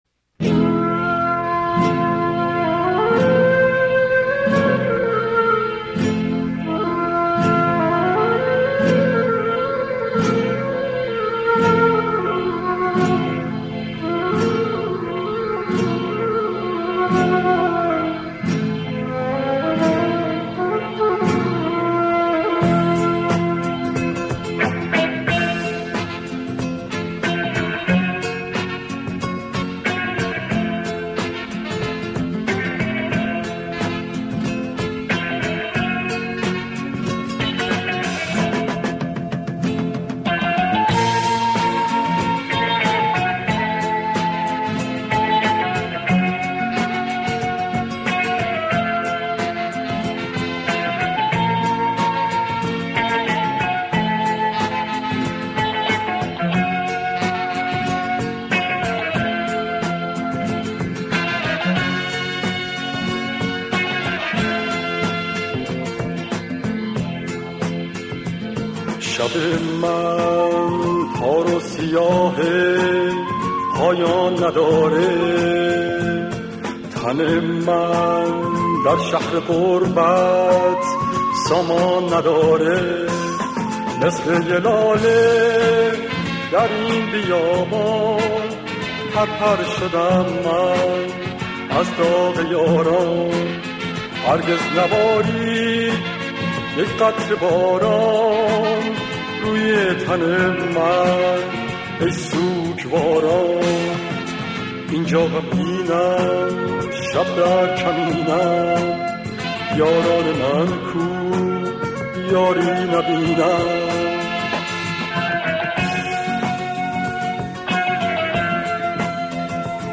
گیتار ۱۲ سیمی
آهنگسازی متأثر از موسیقی راک غربی
موسیقی پاپ ایران